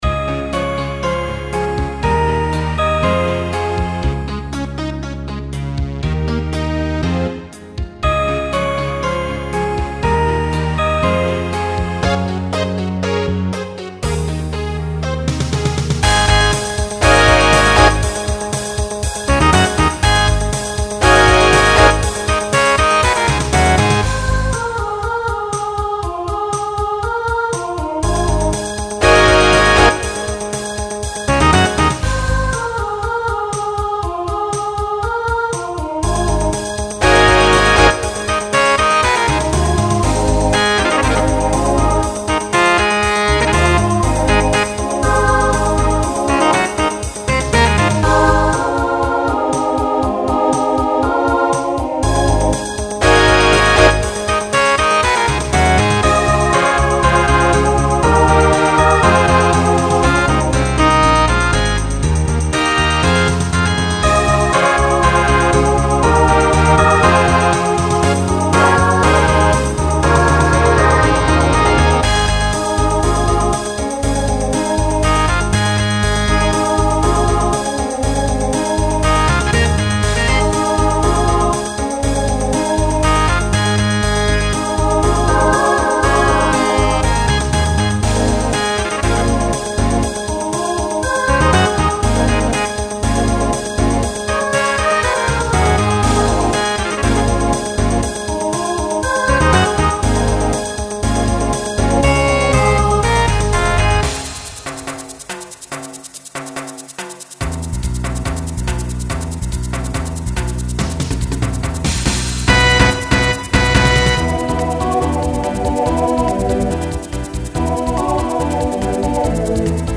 SSA/TTB/SATB
Broadway/Film , Latin , Pop/Dance
Transition Voicing Mixed